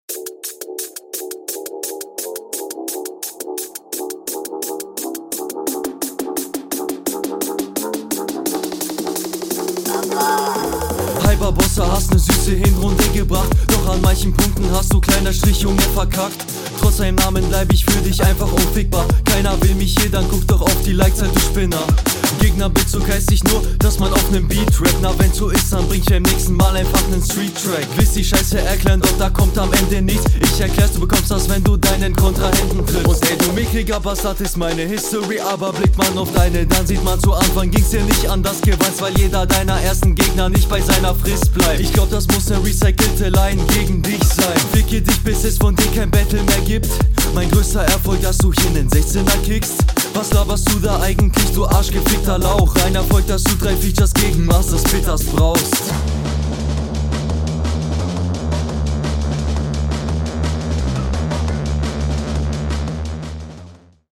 Flowtechnisch ein bisschen mehr probiert und ich mag auch den Stimmeinsatz leicht lieber. Die anspruchsvollere …
Gerade wenn die Drums wegfallen, fällt der Takt insgesamt für dich weg, das ist gerade …
mag deine Stimme bro